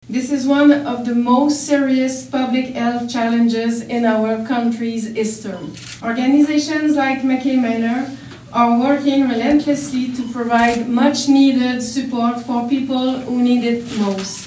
Élisabeth Brière Parliamentary Secretary to the Minister of Families, Children and Social Development and the Minister of Mental Health and Addictions and Associate Minister of Health, joined a number of officials and dignitaries at MacKay Manor Wednesday to make the announcement.